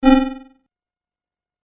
/ F｜システム電子音 / F-02 ｜システム2 さらにシンプルな電子音 カーソル移動などに
システム電子音(シンプル-短) 200 選択 014 特殊
ドゥルルル